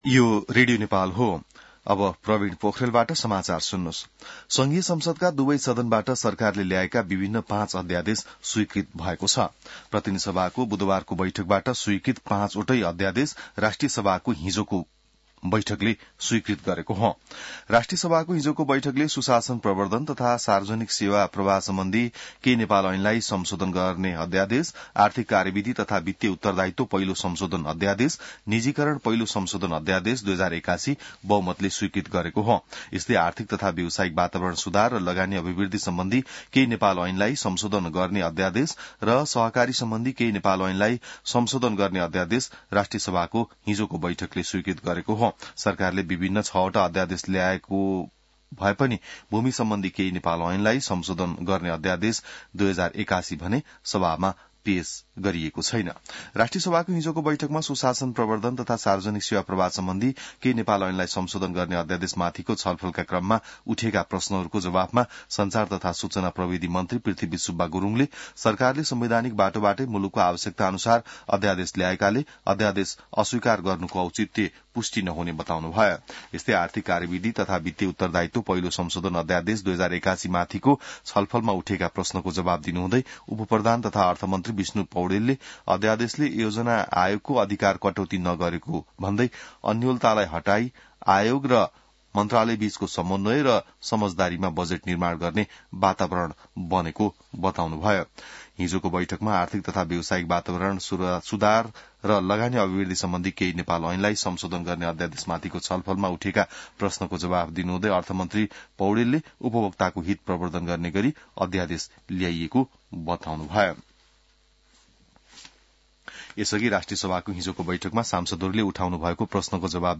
बिहान ६ बजेको नेपाली समाचार : २४ फागुन , २०८१